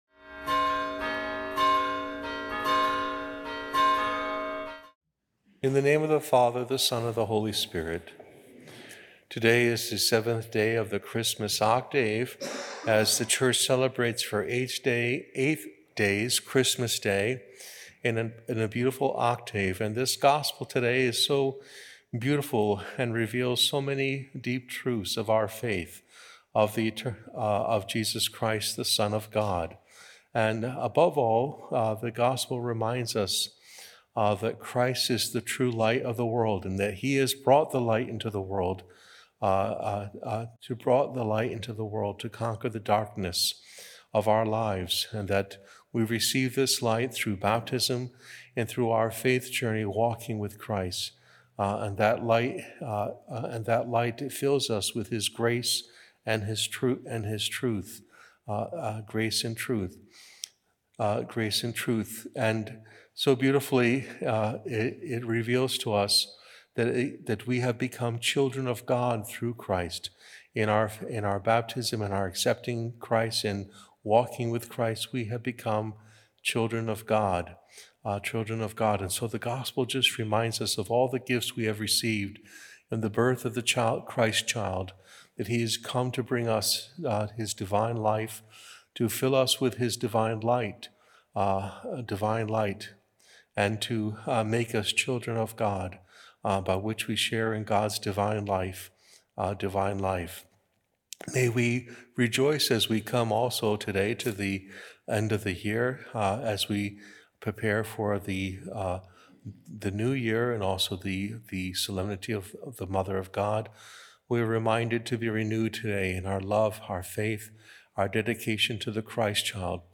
Homily
Mass: 7th Day in Octave